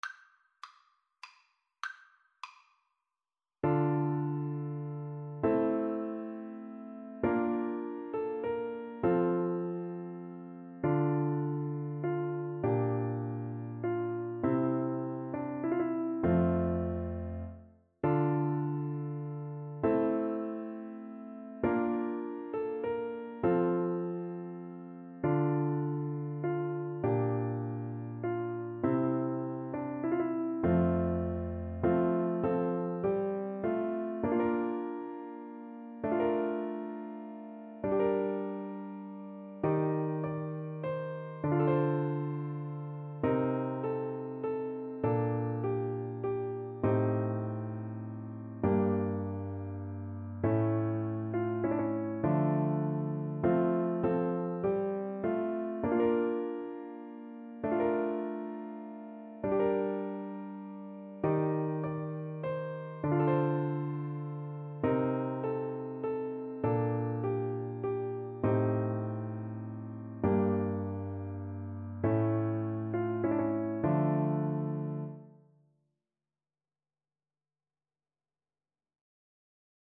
Play (or use space bar on your keyboard) Pause Music Playalong - Piano Accompaniment Playalong Band Accompaniment not yet available reset tempo print settings full screen
D minor (Sounding Pitch) (View more D minor Music for Voice )
3/2 (View more 3/2 Music)
Classical (View more Classical Voice Music)